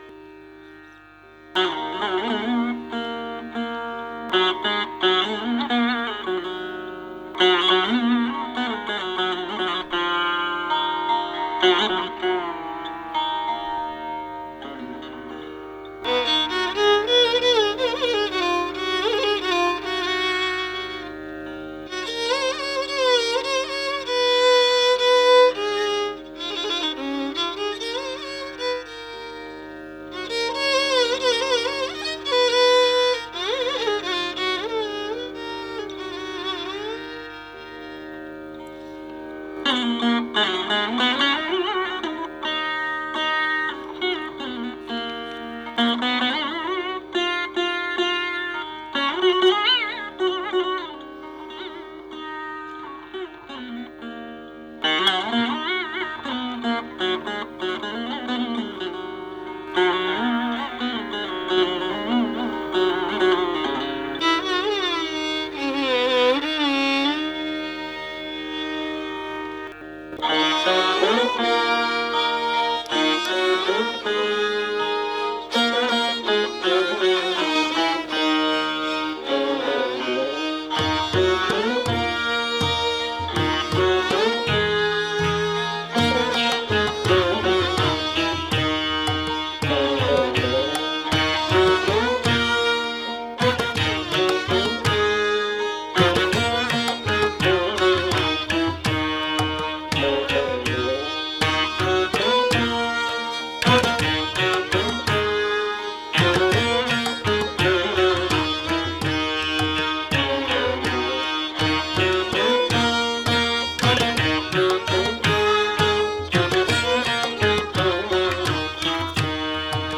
SINDHU PRAVAHAM - An Orchestral Composition
In Sindhubhairavi-Misrachapu Talam